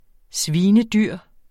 Udtale [ ˈsviːnəˈdyɐ̯ˀ ] Betydninger meget dyr